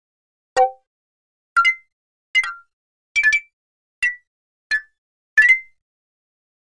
Interface Menu Sound Pack